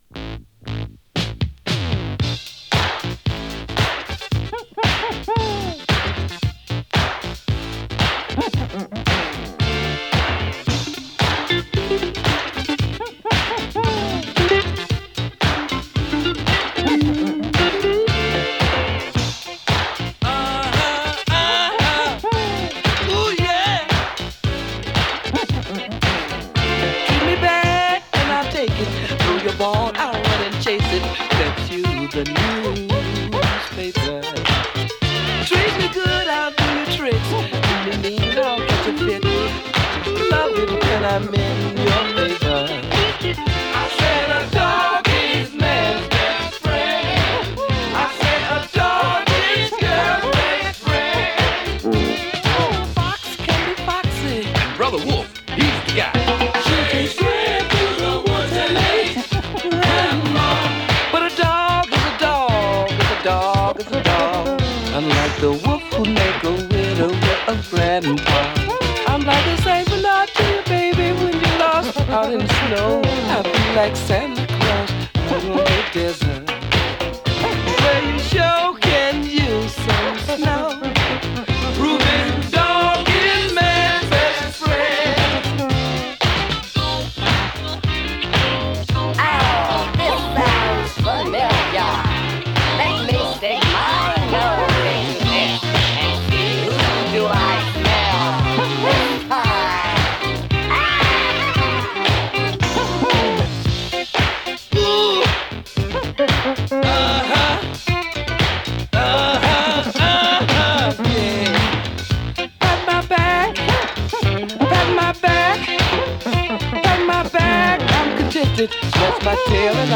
サンプリングねた定番のトボケたP-Funkクラシック！